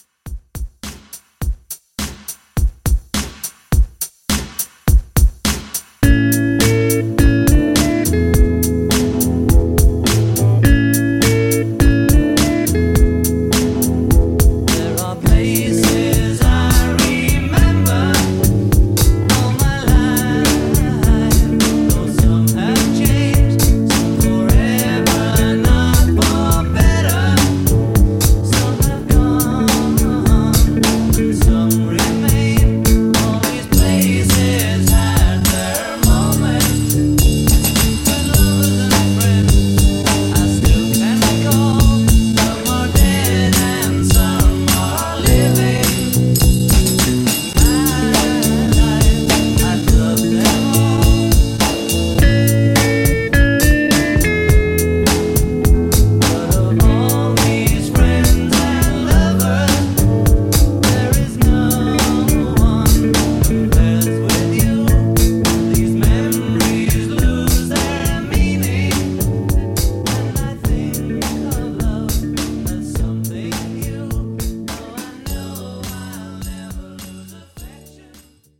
Genre: LATIN
Clean BPM: 94 Time